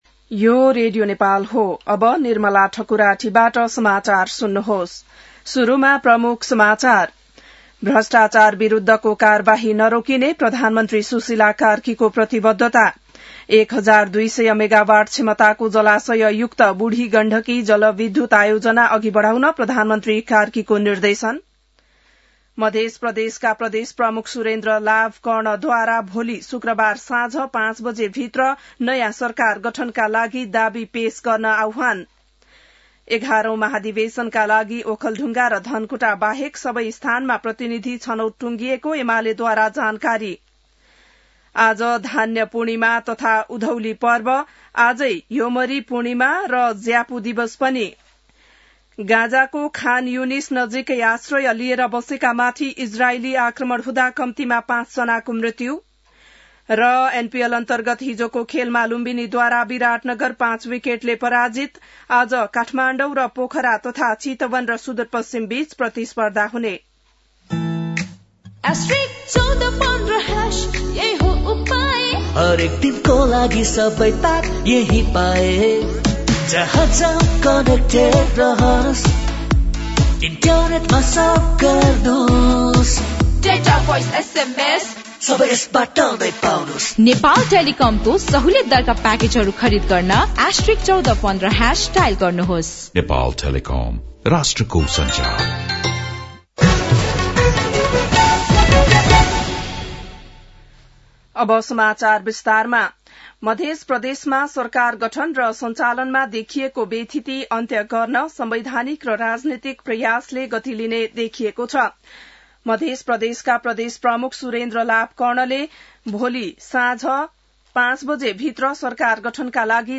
बिहान ७ बजेको नेपाली समाचार : १८ मंसिर , २०८२